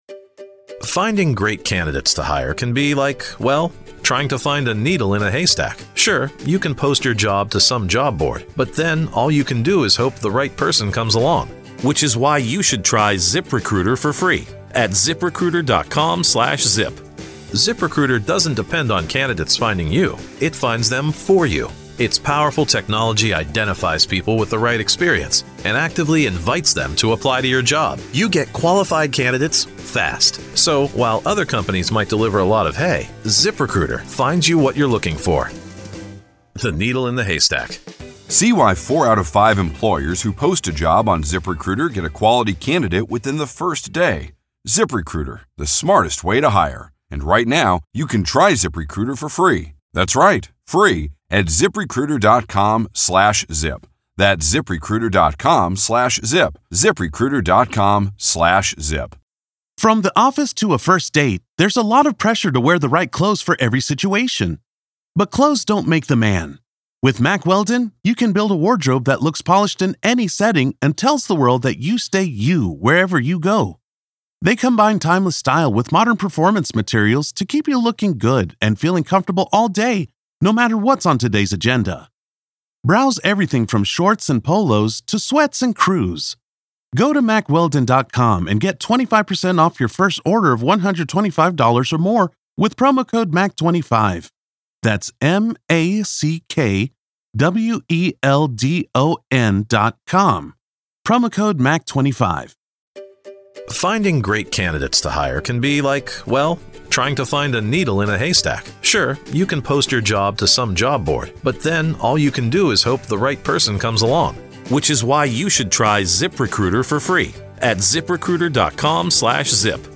Main Points of the Conversation - The defense attempts to connect a 22-year-old altercation to a potential conspiracy in the murder case, using the loyalty shown in the past as a basis for suspicion.